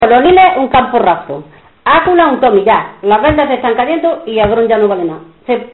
Secciones - Biblioteca de Voces - Cultura oral